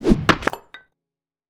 better axe sound.
axe.wav